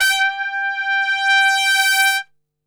G 3 TRPSWL.wav